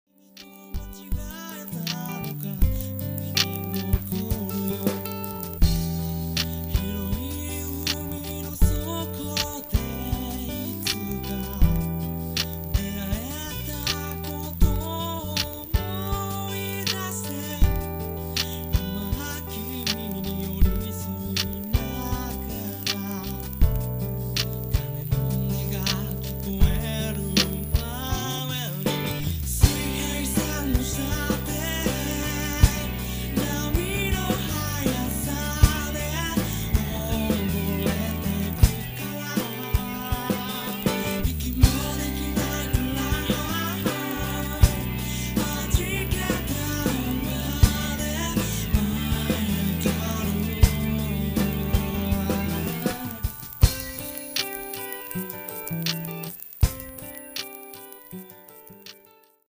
楽曲がいろいろとあり過ぎて、何がなんだか解らないのですが、高いレンジの声がいいなぁ。